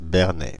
Bernay (French pronunciation: [bɛʁnɛ]
Fr-Bernay.ogg.mp3